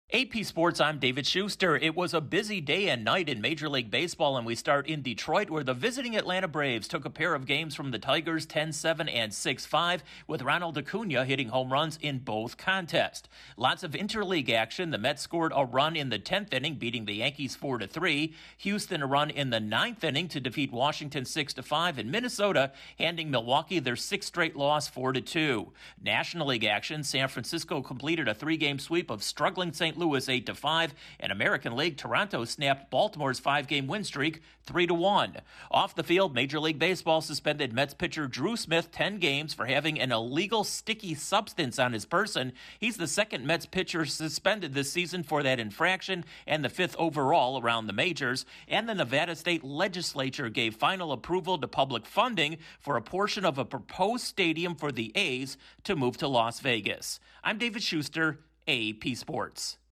The Braves take a pair from the Tigers, the Mets get a walk-off win, the Astros pull out a win, the Brewers continue to struggle, a Mets pitcher is punished and the A's take another step toward Las Vegas. Correspondent